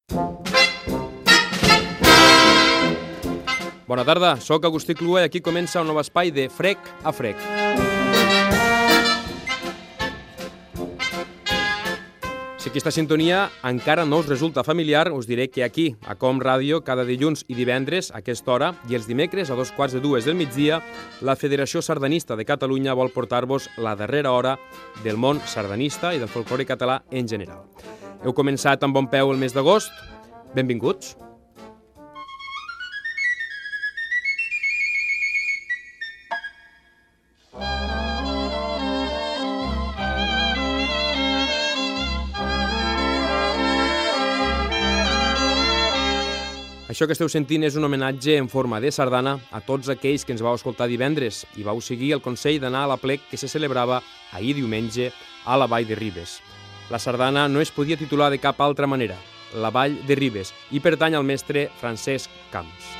Presentació del programa sardanístic i tema musical
Musical